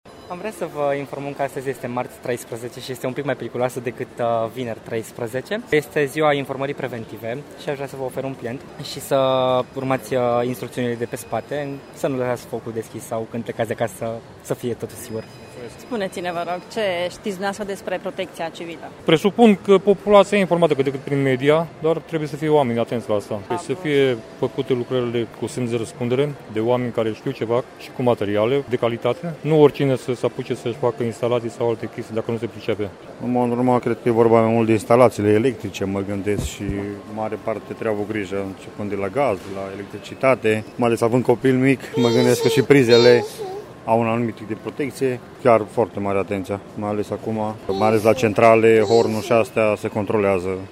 O zi de marți-13 poate fi mai periculoasă decât o zi de vineri-13, spun voluntarii ISU :